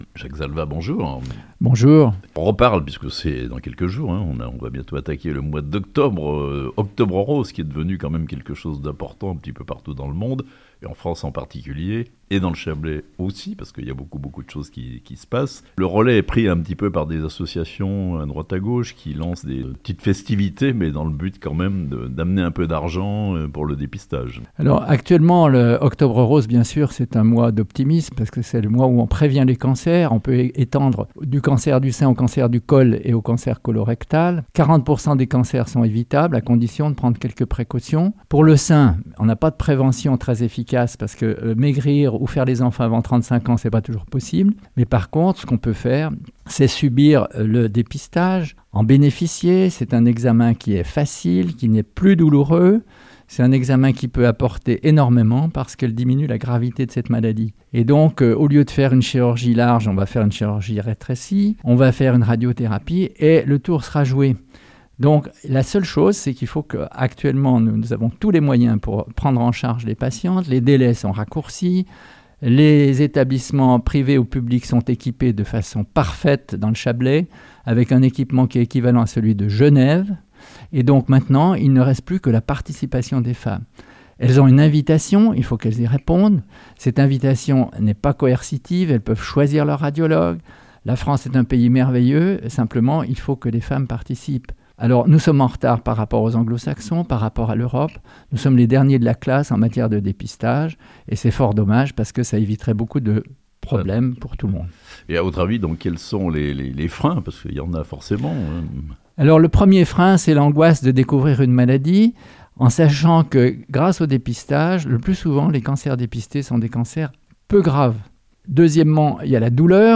Octobre Rose, c'est un coup de projecteur important sur ce fléau qu'est le cancer du sein (interview)